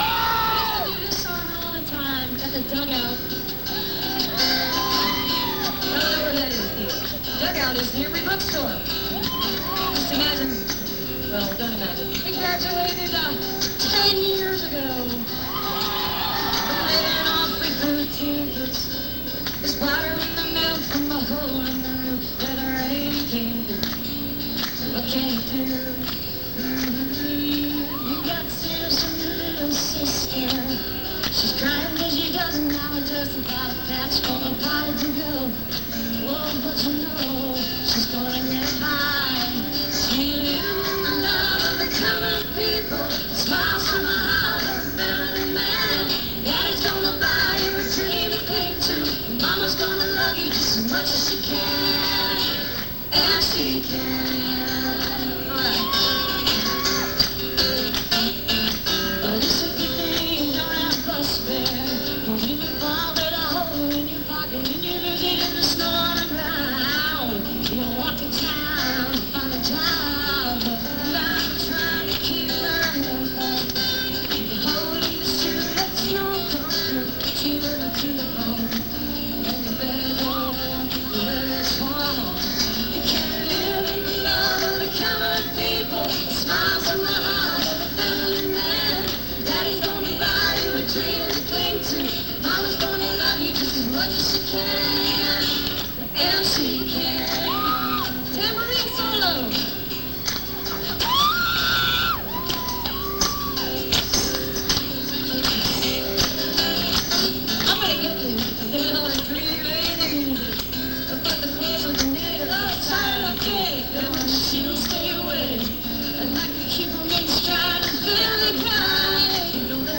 (with group)